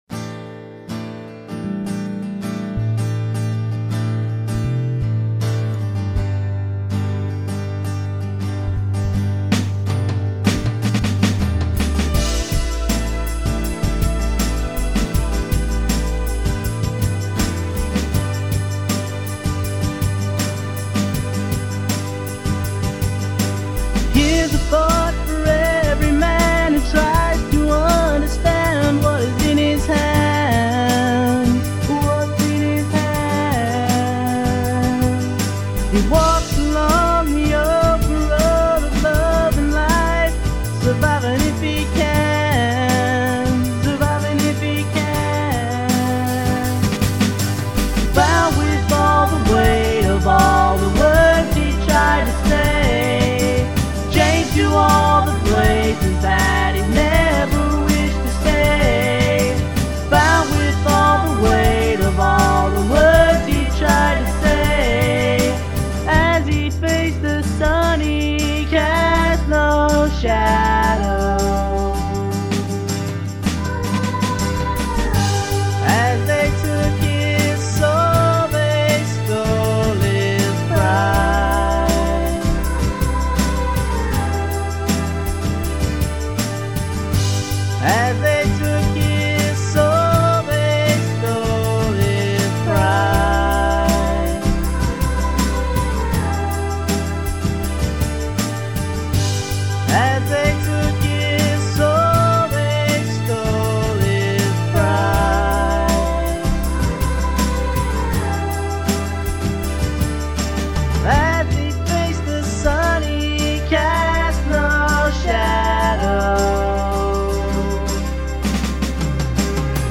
Home/Studio Recordings